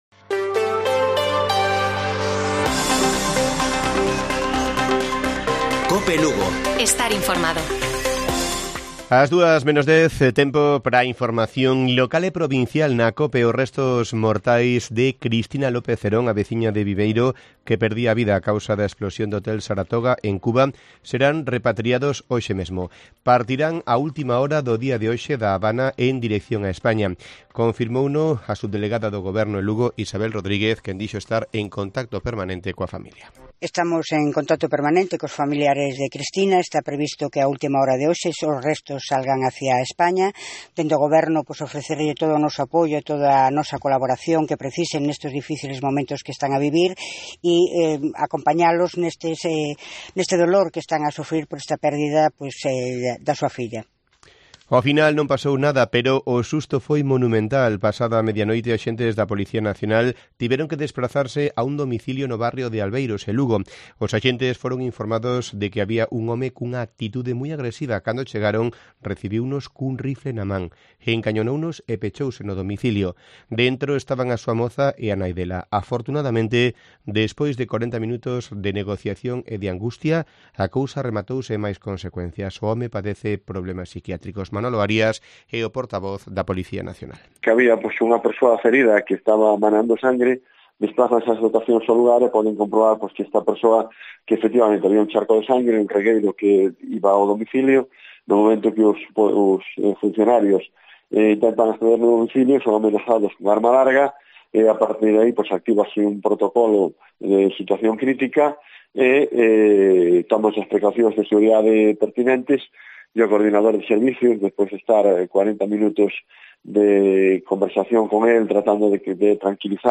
Informativo Mediodía de Cope Lugo. 11 de mayo. 13:50 horas